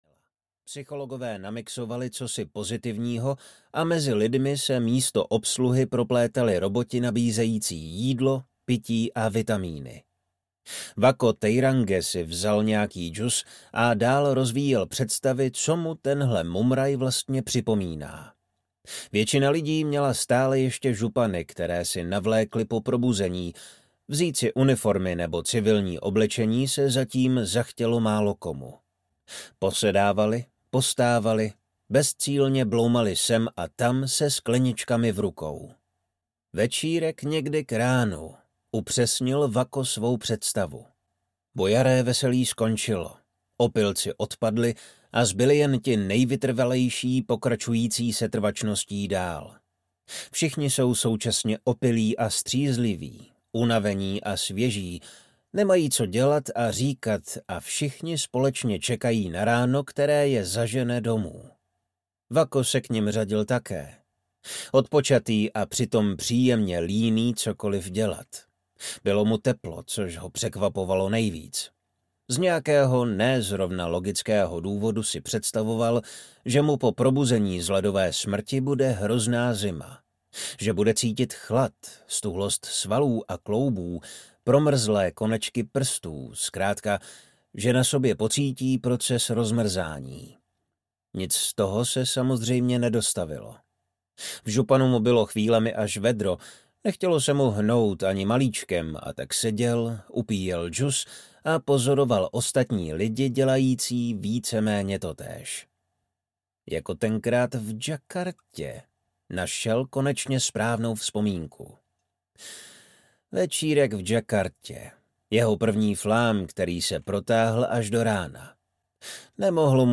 Zpráva z Hádu audiokniha
Ukázka z knihy